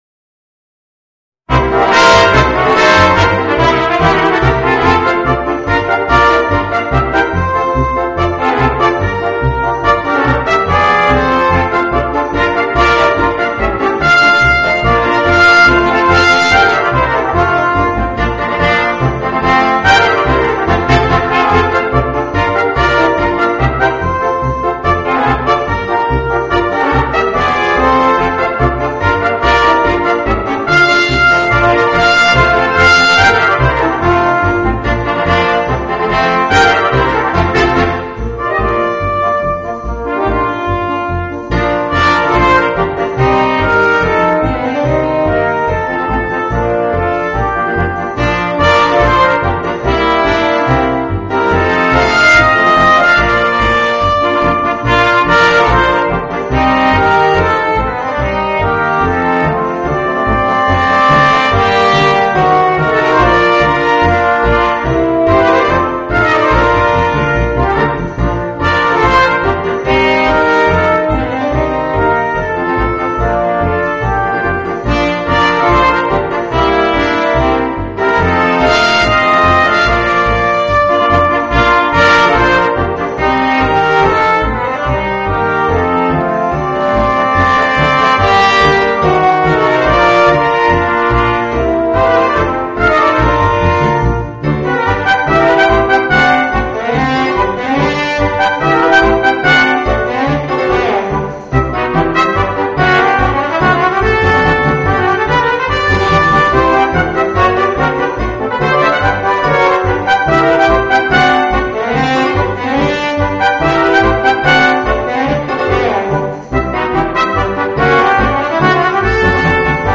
Voicing: 3 Trumpets, Horn and Trombone